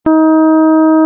Οἱ ἤχοι ἔχουν παραχθεῖ μὲ ὑπολογιστὴ μὲ ὑπέρθεση ἀρμονικῶν.